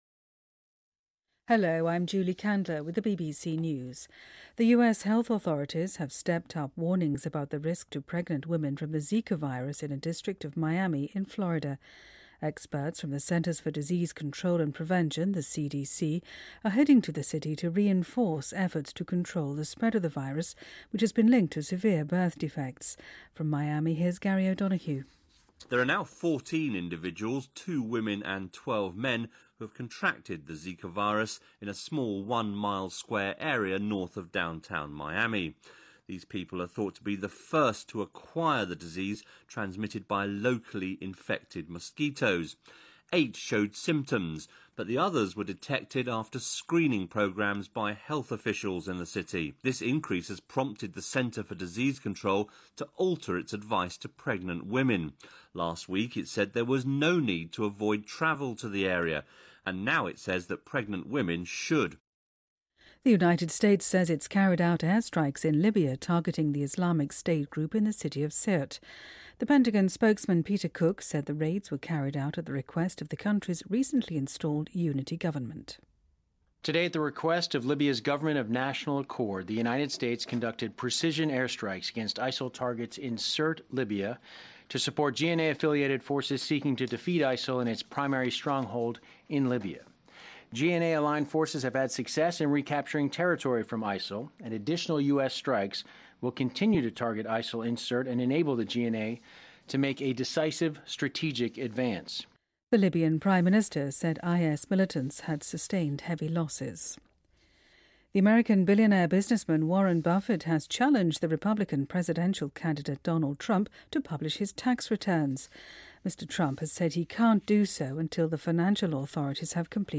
BBC news,股神巴菲特力挺希拉里 挑战特朗普一起晒税单